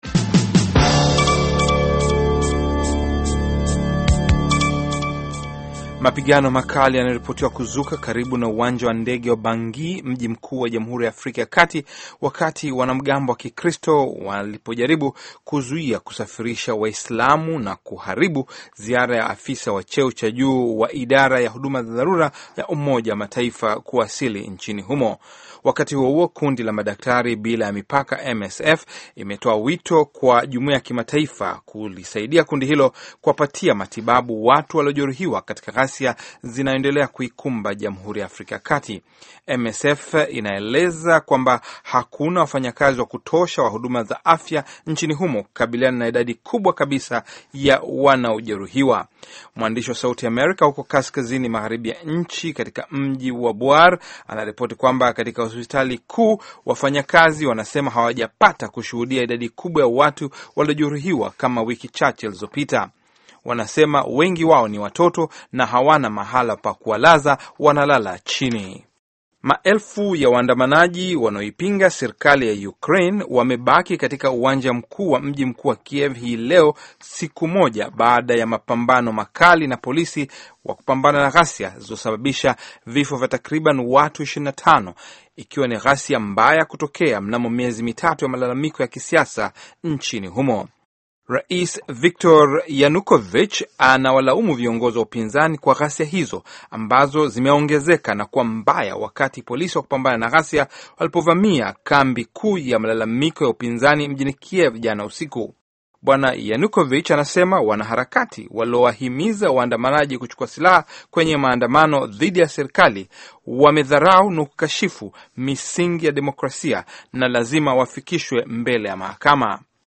Taarifa ya Habari VOA Swahili - 4:29